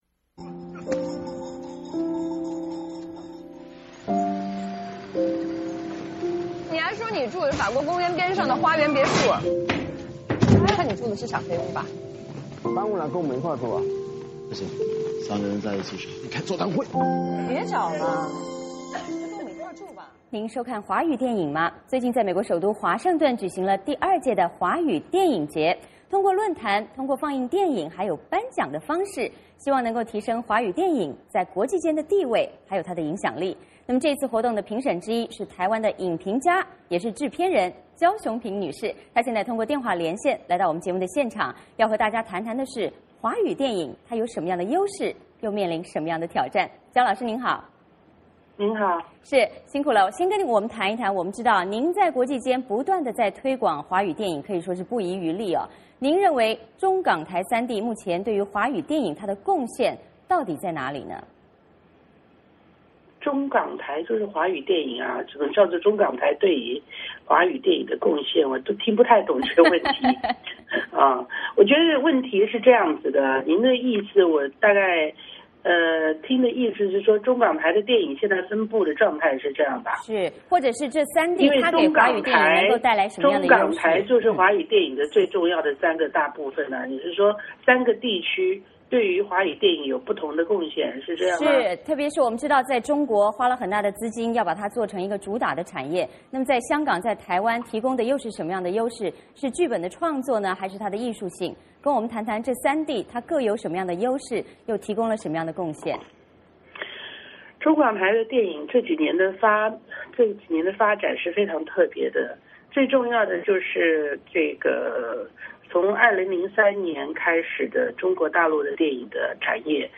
最近美国首都华盛顿举行第二届华语电影节，通过论坛，放映电影以及颁奖典礼的方式，希望提升华语电影在国际间的地位和影响。这次活动的评审之一，台湾影评家也是制片人焦雄屏女士，通过连线来到VOA卫视新闻，要和我们谈谈华语电影的优势与挑战。